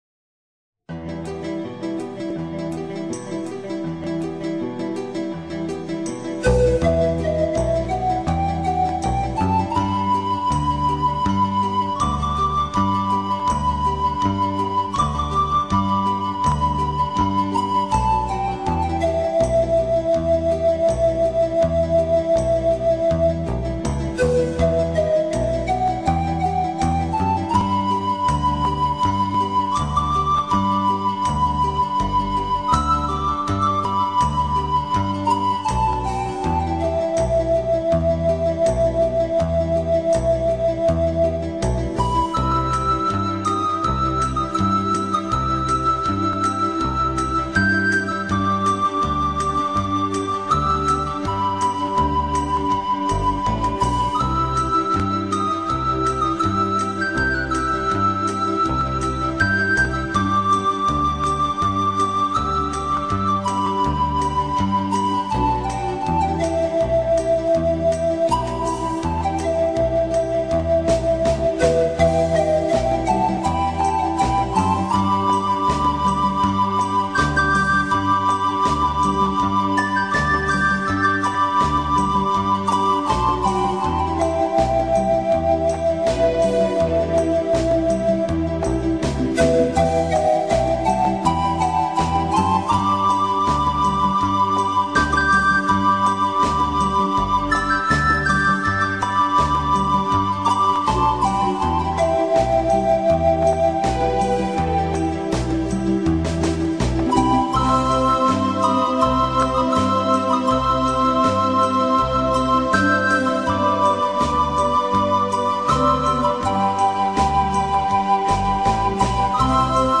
KGmwu5rjEg2_El-cóndor-pasa-instrumental-.mp3